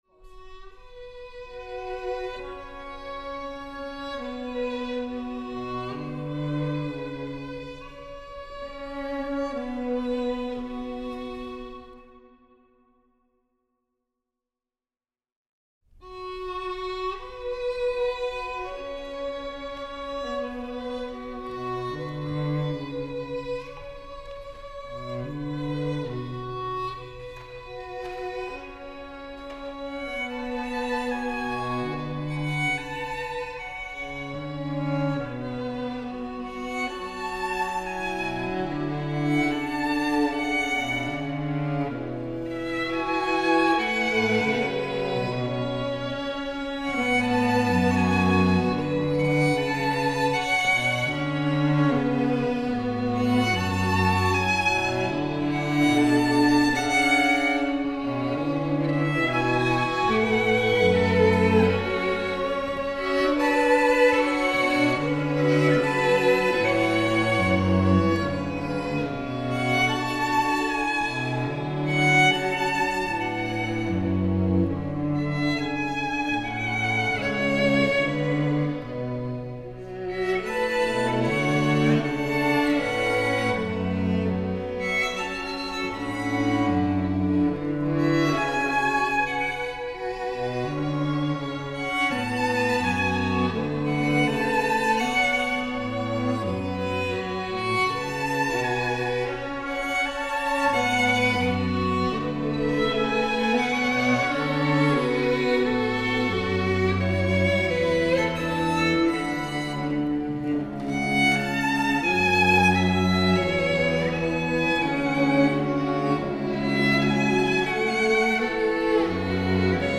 For string orchestra and harpsichord
[thumbnail of Live Recording 290216] Audio (Live Recording 290216)
This is a three-movement work conceived within a quasi-sinfonia form (fast-slow-fast) exploring the concept of pseudo-modulation within tonal stasis.
Building chords upon the whole-tone and perfect-fourth intervals (instead of the largely triadic harmony the ensemble are used to) shaped modal and consonant phrases sympathetic to their usual repertoire, whilst producing original music that is clearly a product of the twenty-first century.